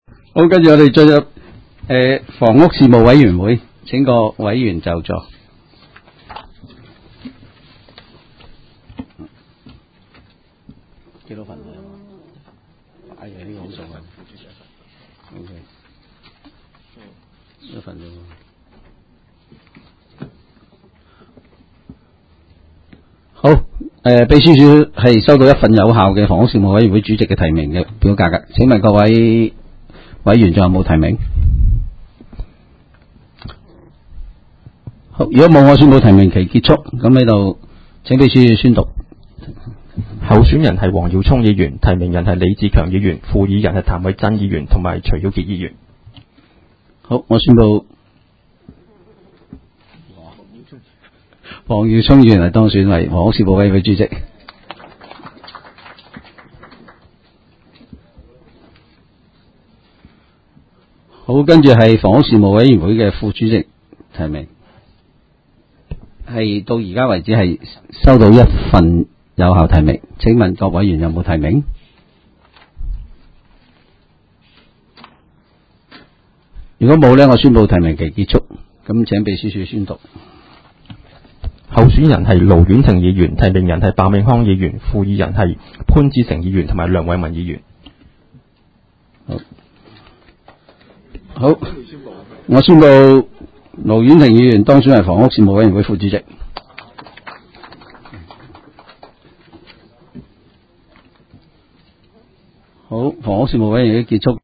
委员会会议的录音记录
房屋事务委员会第一次特别会议会议 日期: 2018-01-03 (星期三) 时间: 下午3时54分 地点: 香港葵涌兴芳路166-174号 葵兴政府合署10楼 葵青民政事务处会议室 议程 讨论时间 1 选举房屋事务委员会主席及副主席 00:01:44 全部展开 全部收回 议程:1 选举房屋事务委员会主席及副主席 讨论时间: 00:01:44 前一页 返回页首 如欲参阅以上文件所载档案较大的附件或受版权保护的附件，请向 区议会秘书处 或有关版权持有人（按情况）查询。